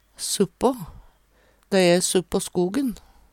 DIALEKTORD PÅ NORMERT NORSK suppå sør på Eksempel på bruk Dei æ suppå skogen. See also noLpå (Veggli) Hør på dette ordet Ordklasse: Preposisjon Attende til søk